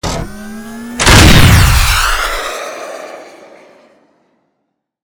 battlesuit_rocket.wav